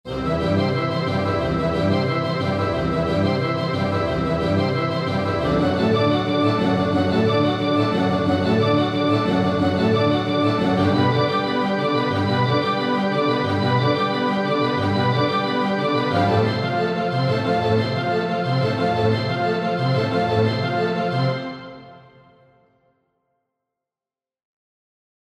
Dieser vermittelt über den normalen Stereoausgang ein räumliches, binaurales Signal, sodass über Kopfhörer ein dreidimensionaler Surroundklang zu hören ist.
Hier das SAD 3D-Signal, wie gesagt, nur über Kopfhörer genießbar:
Als Umgebung habe ich im SAD-Plug-in den Abhörraum des Luna Studio 2 gewählt.